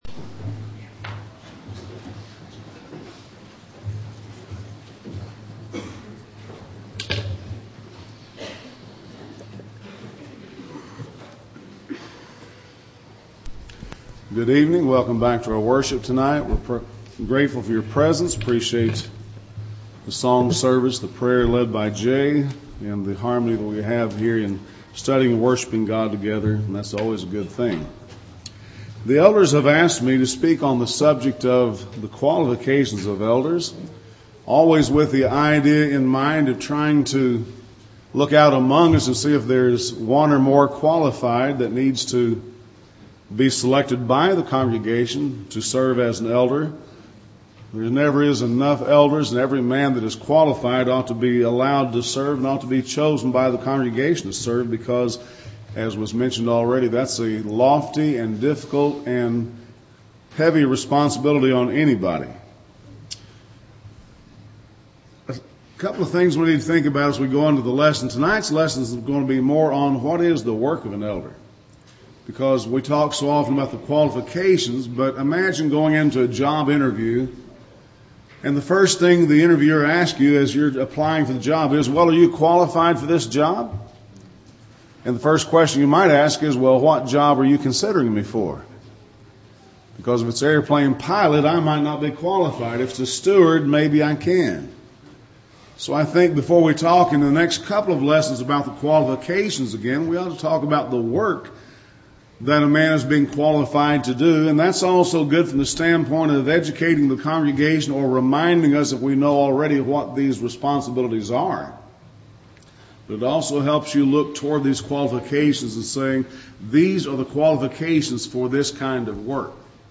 Bible Teaching on Elders | Mining The Scripture